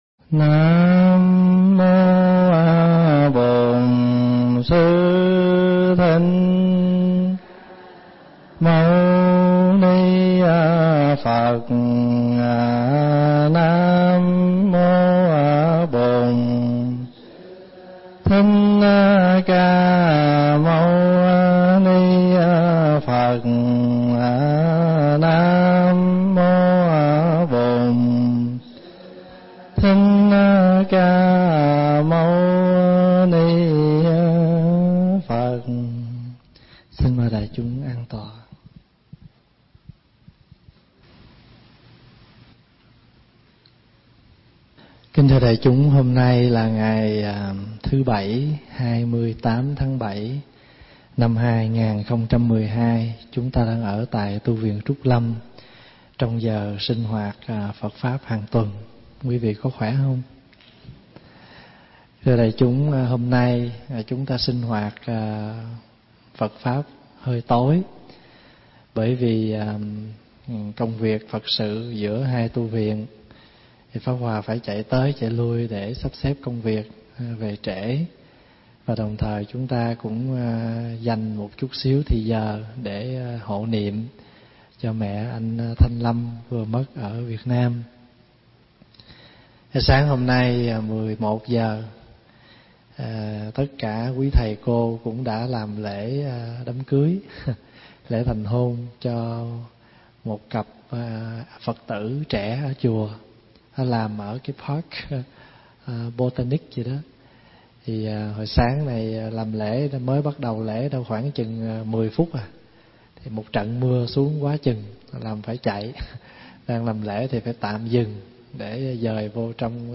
Nghe Mp3 thuyết pháp Phật Hay Ma
thuyết giảng tại Tu Viện Trúc Lâm, Canada